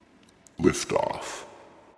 描述：不知道听起来应该是什么，实际上它是迷你吸尘器声音的混合物。
标签： 科幻 cryzy AT2020 声音
声道立体声